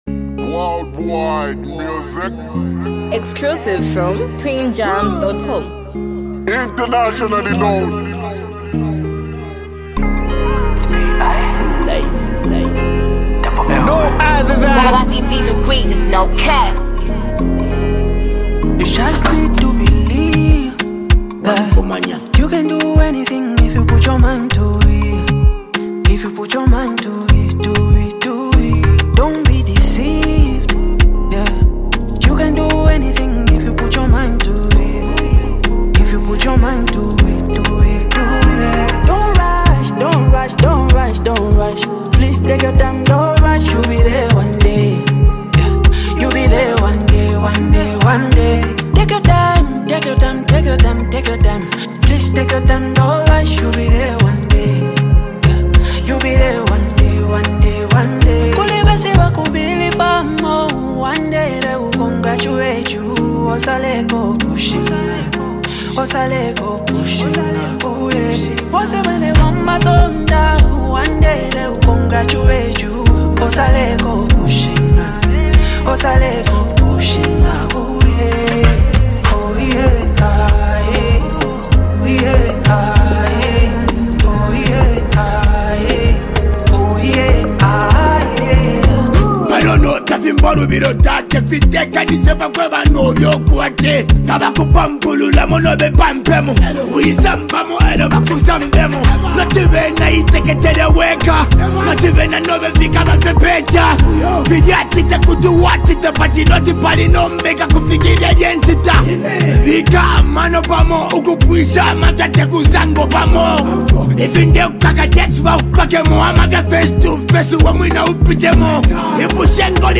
rappers